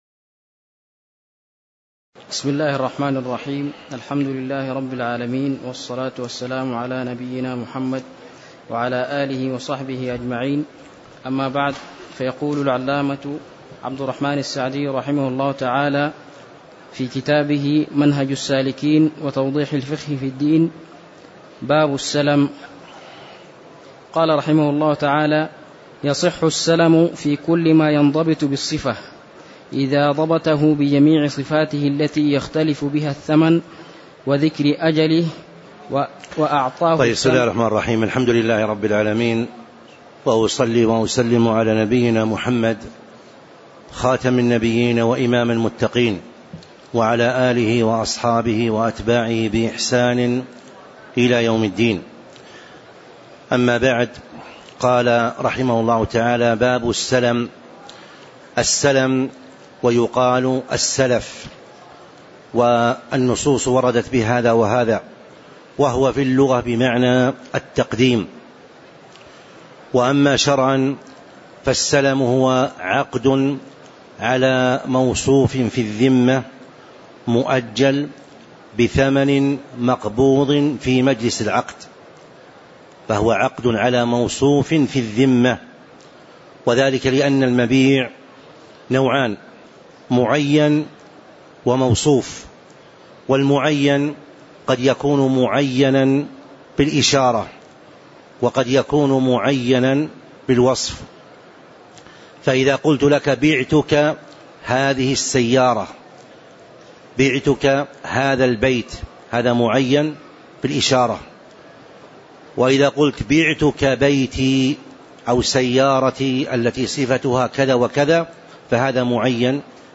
تاريخ النشر ٢١ محرم ١٤٤٦ هـ المكان: المسجد النبوي الشيخ